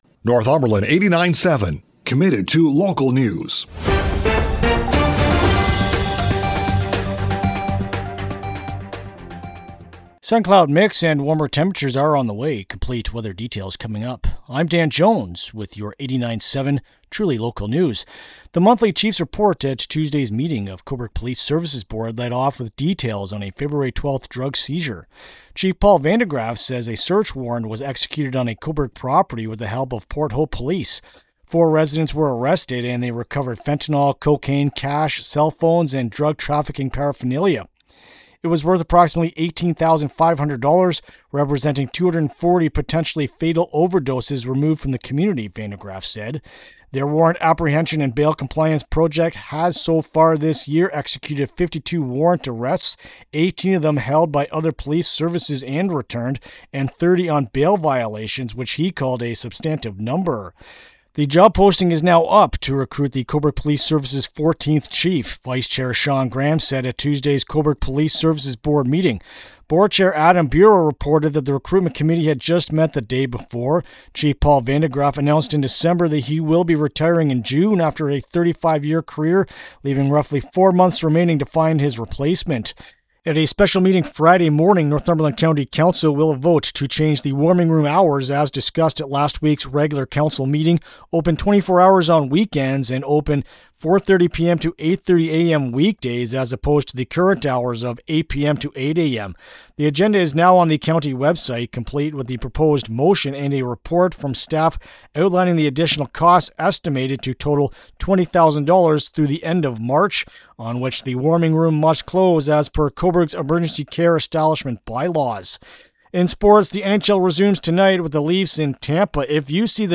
Wed-Feb-25-PM-News-2.mp3